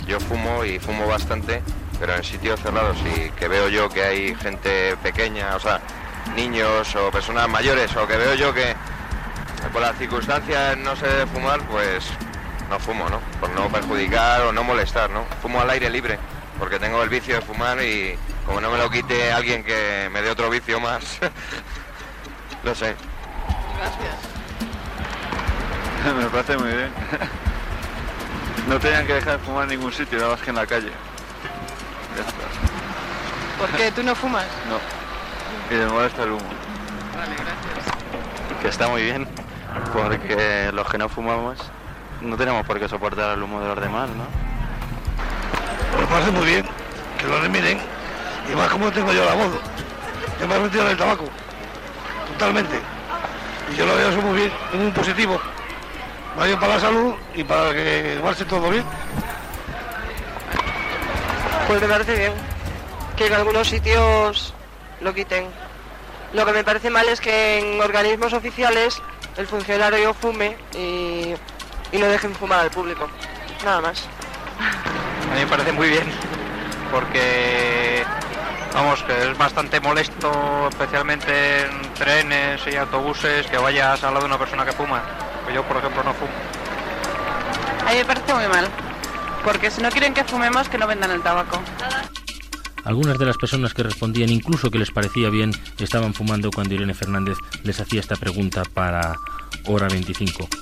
Enquesta al carrer de Madrid sobre l'opinió de la ciutadania sobre la Llei antitabac.
Informatiu